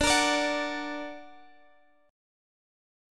Listen to D+ strummed